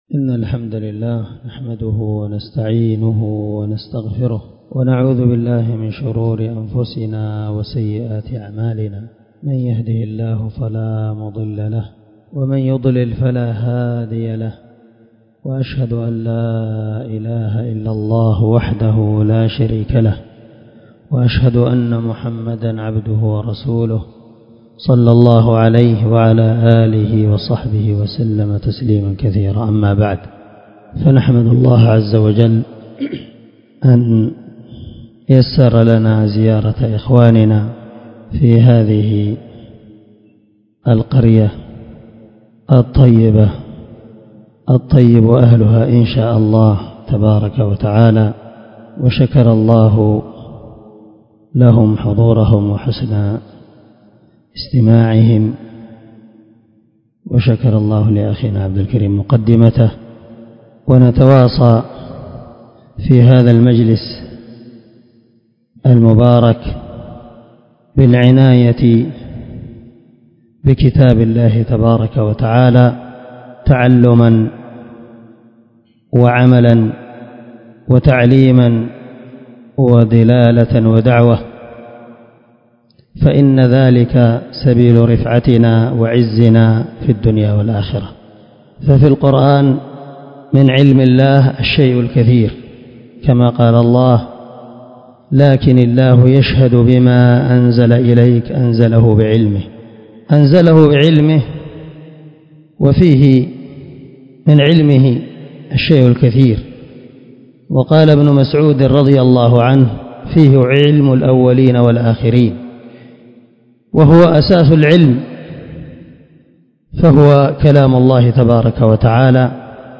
محاضرة
العناية بكتاب الله ) سجلت في مسجد مامر ليلة 20 من شهر شوال 1443